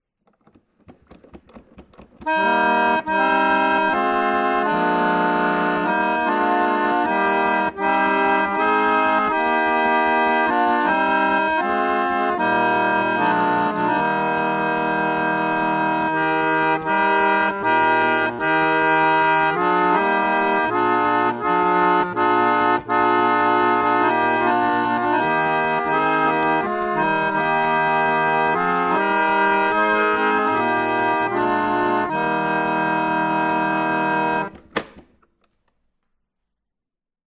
Concert Roller Organ
Fonctionne à manivelle par aspiration.
Le cylindre est noté de manière hélicoïdale sur 3 tours.
Système de ressort en fin d'air pour retour en position initiale.
Musée de Musique Mécanique